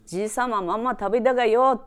Aizu Dialect Database
Type: Statement
Final intonation: Rising
Location: Showamura/昭和村
Sex: Female